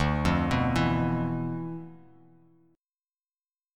Dm6add9 Chord
Listen to Dm6add9 strummed